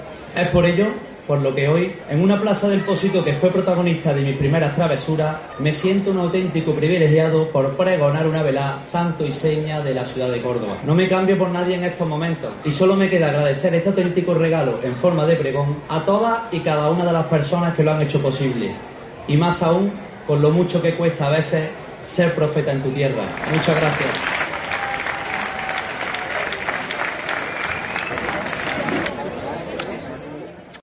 en una parte de su pregón